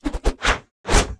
binf_swing1.wav